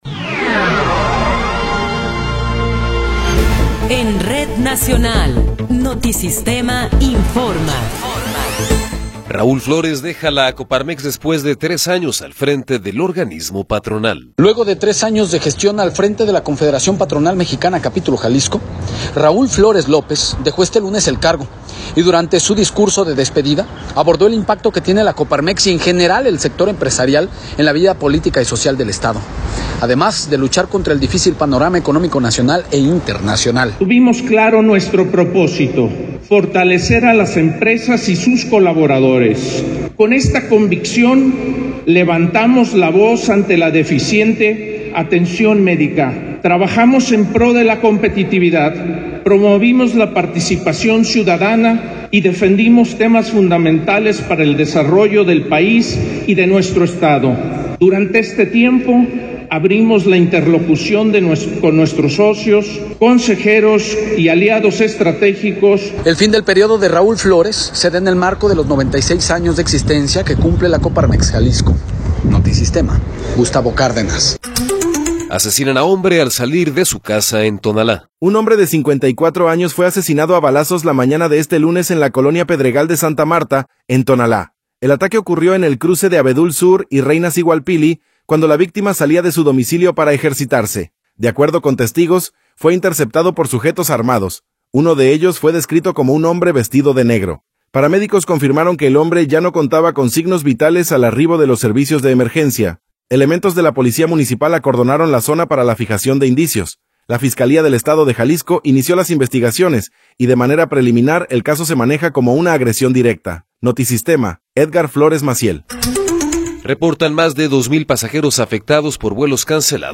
Noticiero 12 hrs. – 20 de Abril de 2026
Resumen informativo Notisistema, la mejor y más completa información cada hora en la hora.